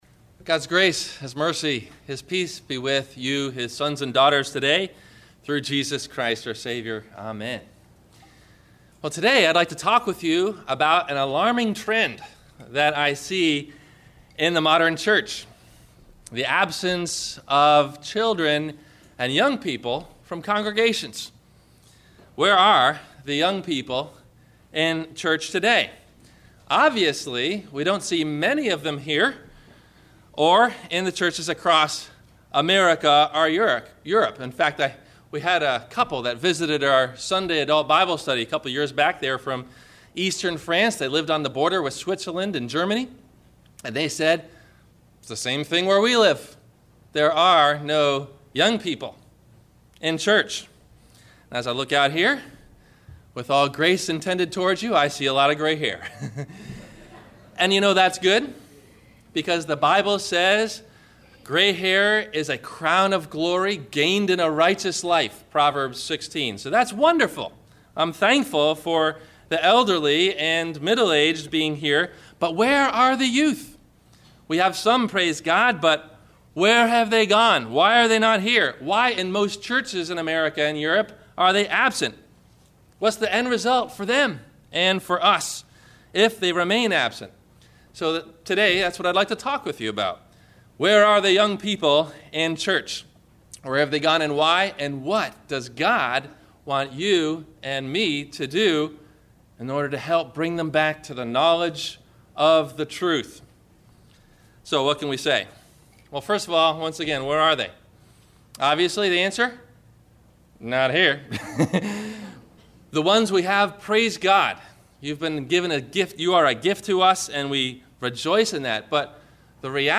Where Are the Young People in Church ?- Sermon – August 04 2013